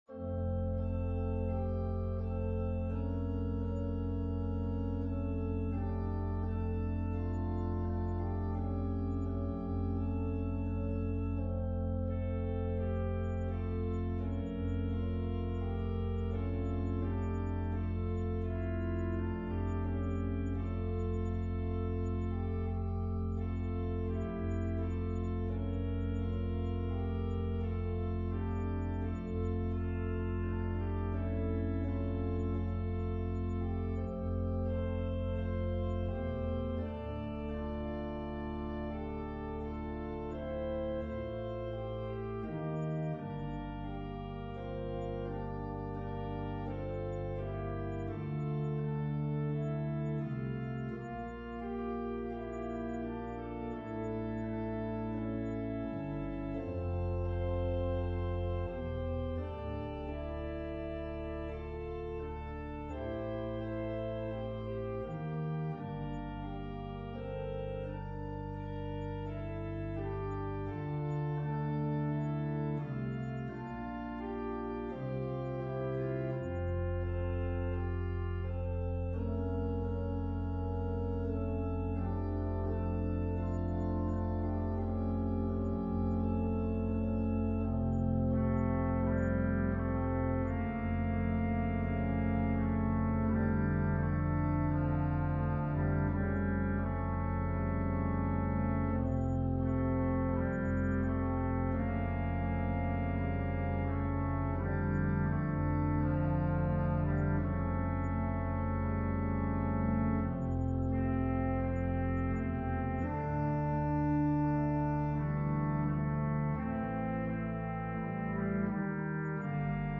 An organ solo arrangement
Voicing/Instrumentation: Organ/Organ Accompaniment We also have other 53 arrangements of " Be Still, My Soul ".